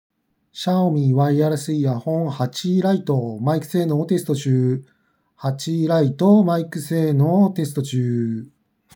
比較的クリアな音質で3,000円台なら合格ライン以上の性能
平均より性能が高いマイク性能
「Xiaomi REDMI Buds 8 Lite」マイクテスト
音のこもりも少なく5,000円以下ではかなりクリアな音質で聴けます。